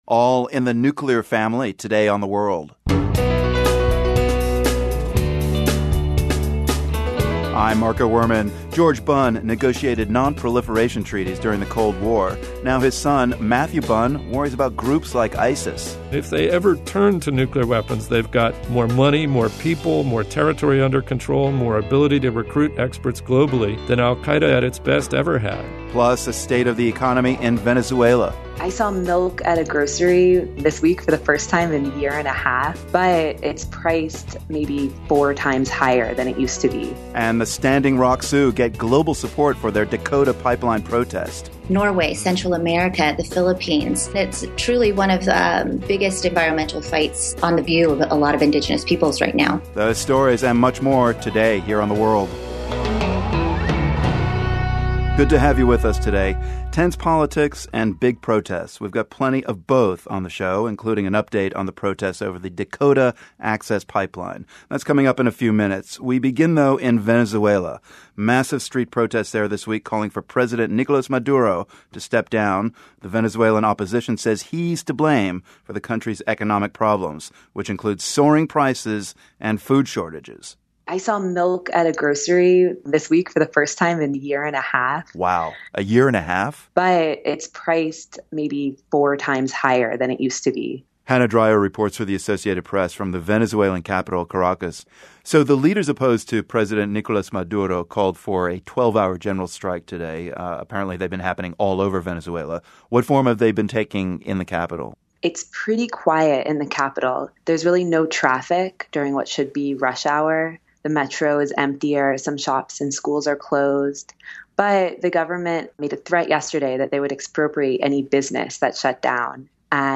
We get the latest on street protests in Venezuela, and find out what's happening with the pipeline protests in North Dakota. We also take a look at a "nuclear" family — as in, we talk to a nuclear policy analyst who reflects on his own work and that of his father, an American diplomat and proliferation expert. Plus, we hear why a Saudi woman left her own country for the relative freedom of Pakistan.